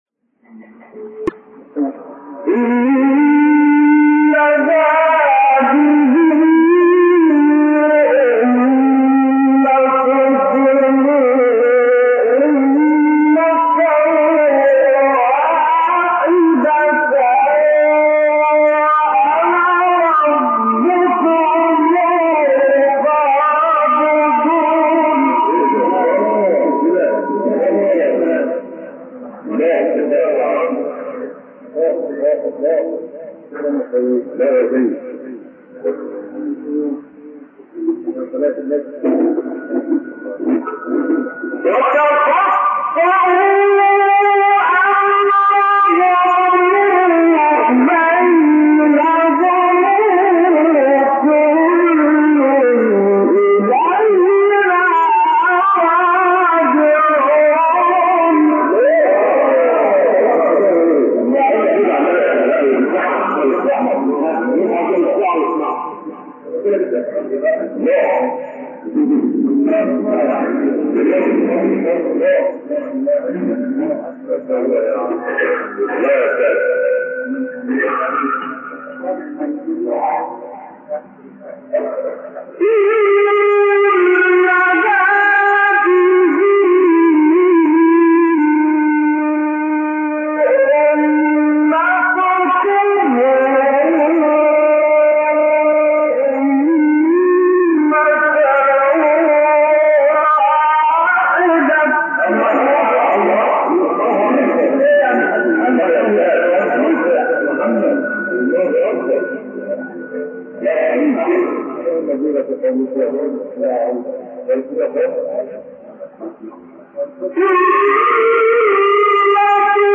سوره : انبیاء آیه : 92-97 استاد : عبدالعزیز حصان مقام : بیات قبلی بعدی